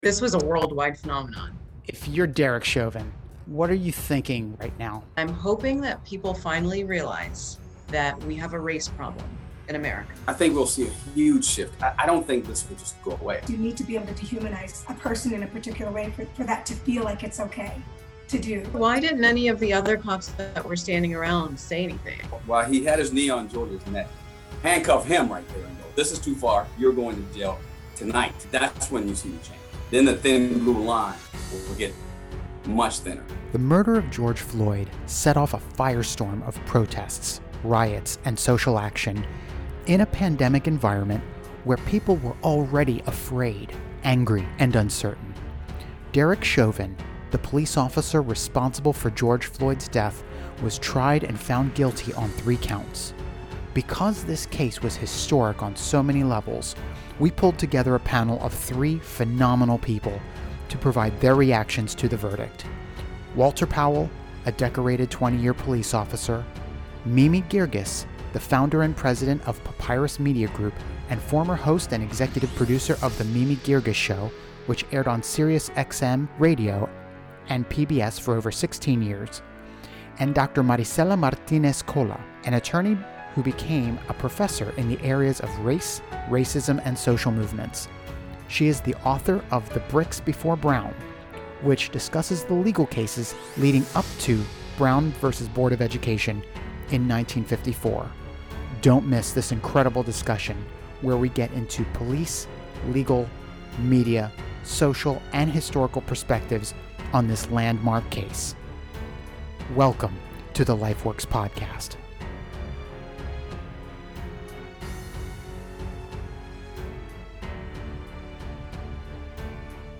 Derek Chauvin, the police officer responsible for George Floyd’s death, was tried and found guilty on three counts. Because this case was historic on so many levels, we pulled a together a panel of three phenomenal people to provide their reactions to the verdict